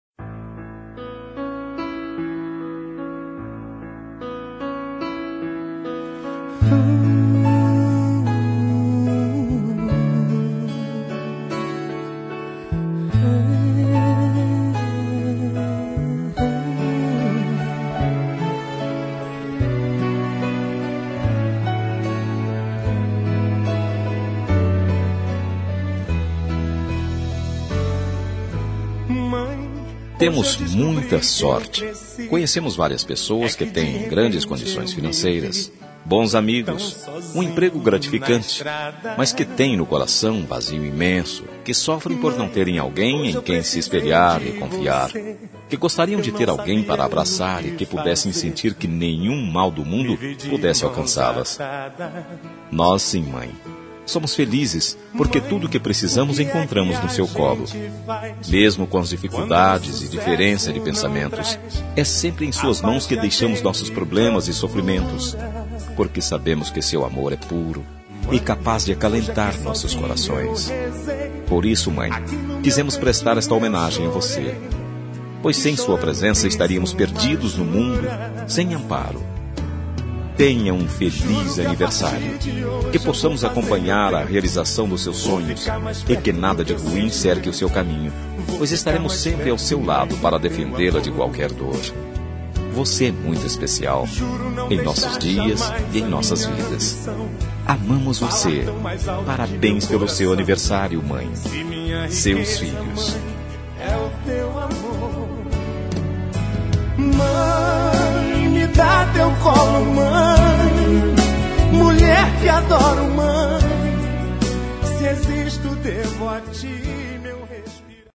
Telemensagem de Aniversário de Mãe – Voz Masculina – Cód: 1433 – Rick e Rener